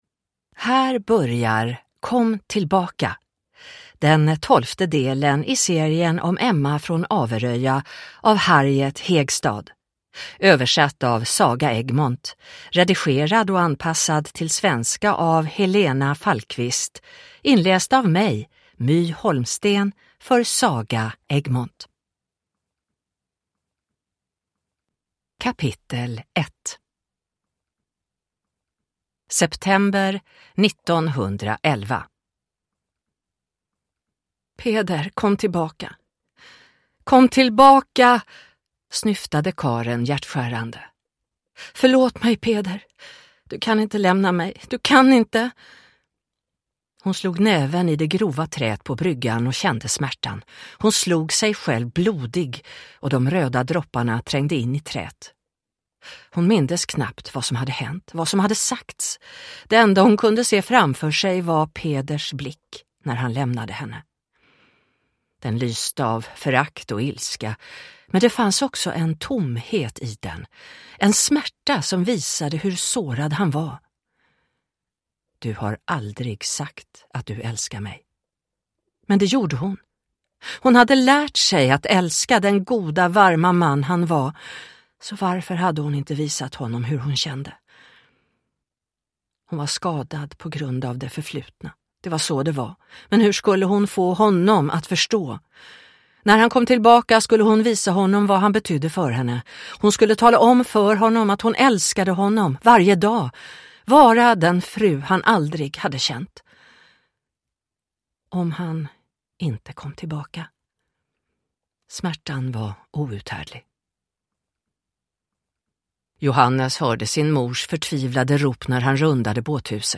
Kom tillbaka – Ljudbok